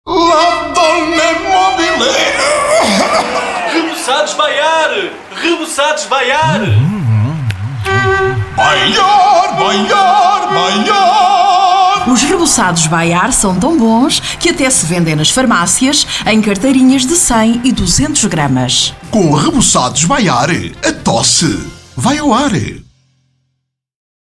Desde sempre, os spots publicitários elaborados pelos Parodiantes comportam um formato de comunicação com sentido humorístico!
Rebuçados Dr Bayard Spot Rádio Novos Parodiantes